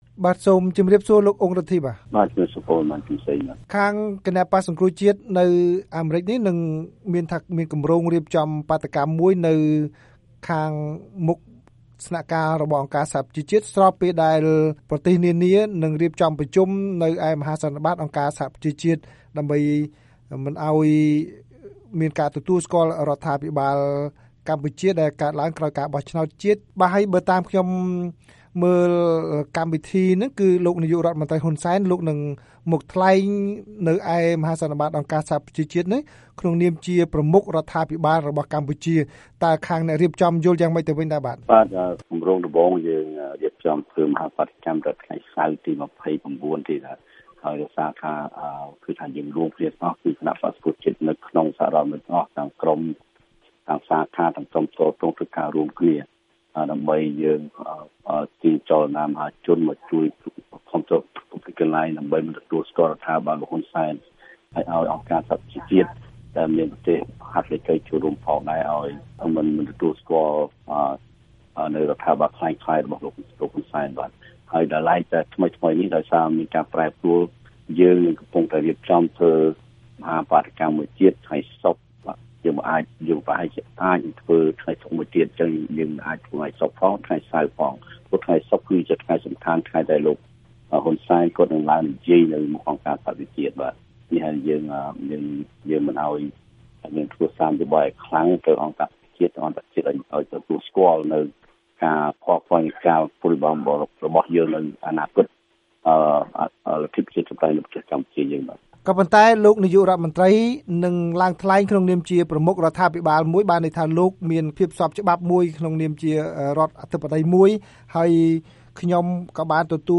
បទសម្ភាសន៍ VOA៖ ខ្មែរនៅអាមេរិកគ្រោងបាតុកម្មពេលលោកហ៊ុន សែន ថ្លែងនៅអង្គការសហប្រជាជាតិ